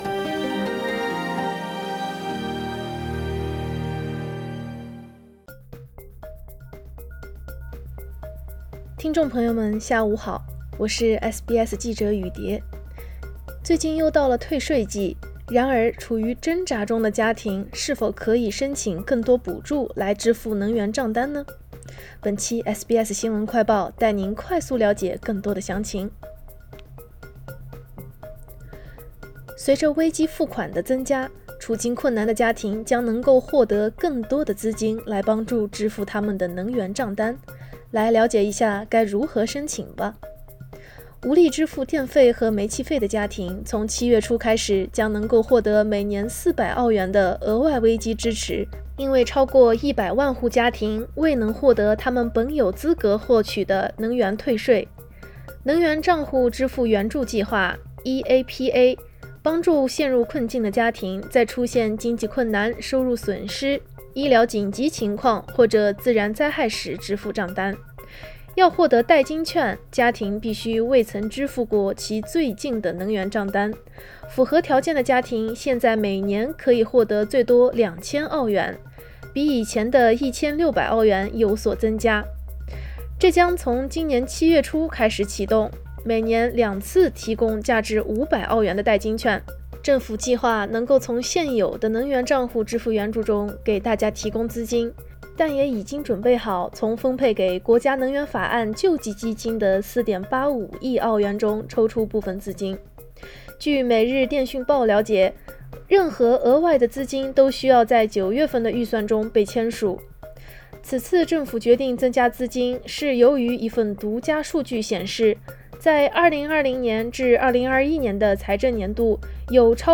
【SBS新闻快报】100多万户家庭未能获得能源退税 政府增加能源账单补贴